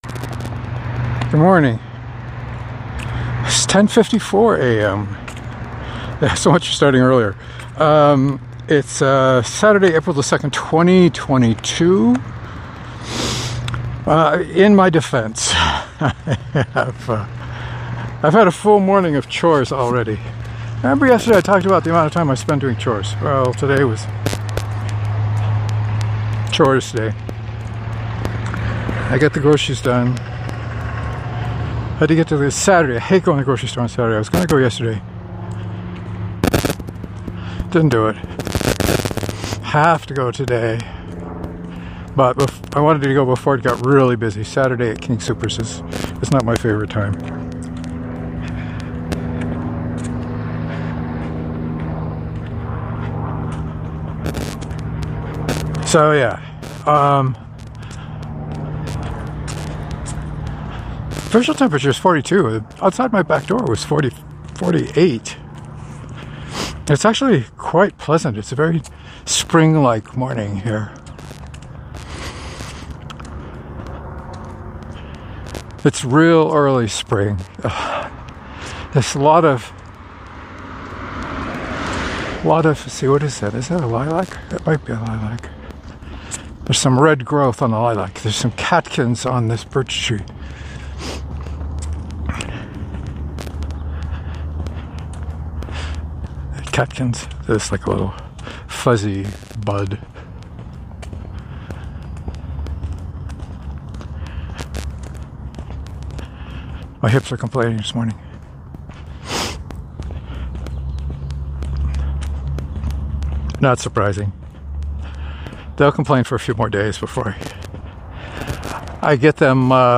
I forgot to turn on airplane mode before recording so there are some annoying artifacts.